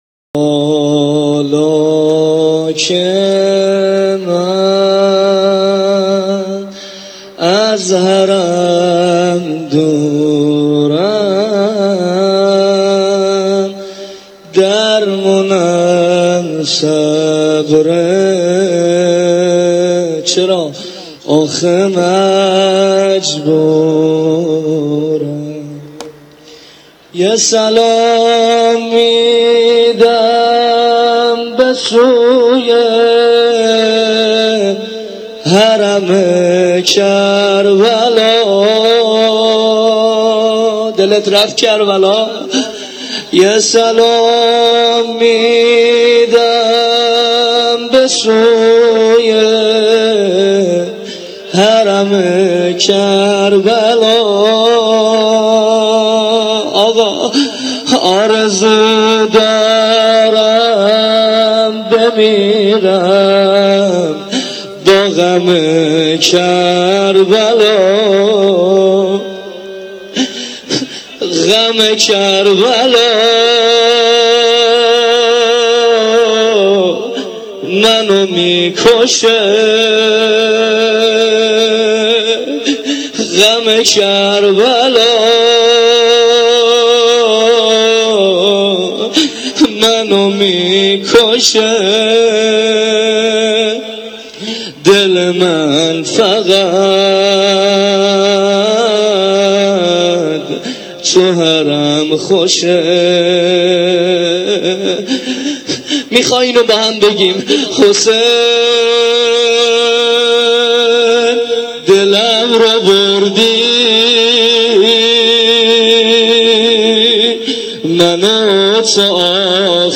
روضه غم دوری از حرم ارباب.m4a
روضه-غم-دوری-از-حرم-ارباب.m4a